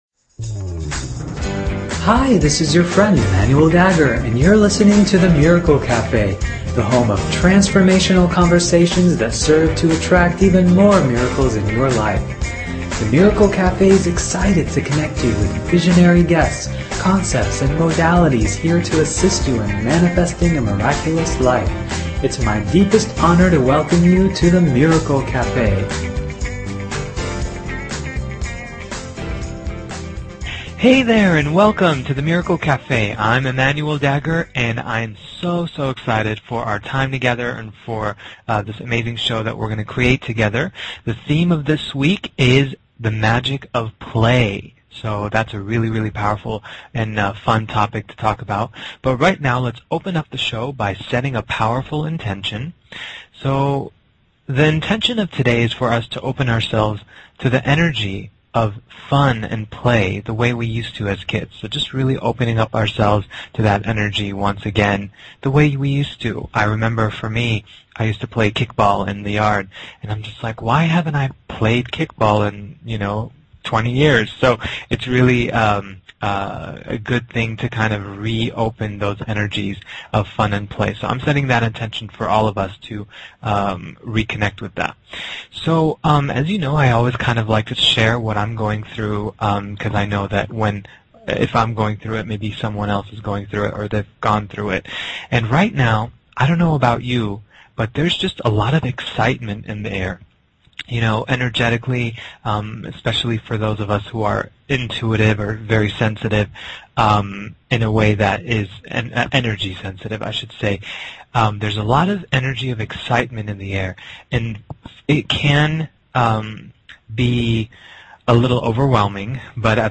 The Miracle Café is a radio show that is here to provide each listener with life-changing insights, processes, and conversations that serve to attract even more miracles and positive transformation in their life!